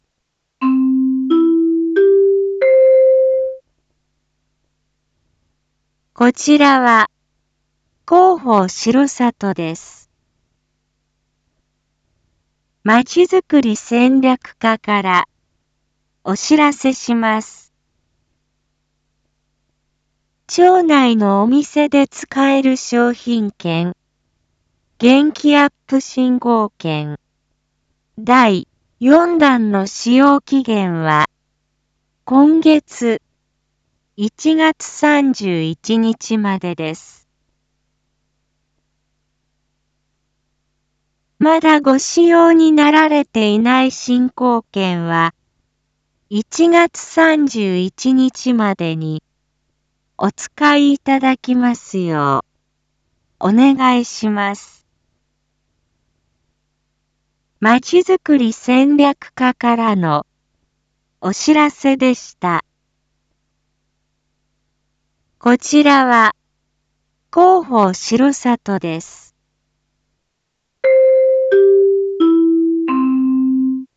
一般放送情報
Back Home 一般放送情報 音声放送 再生 一般放送情報 登録日時：2022-01-21 19:01:20 タイトル：R4.1.22 19時放送 インフォメーション：こちらは、広報しろさとです。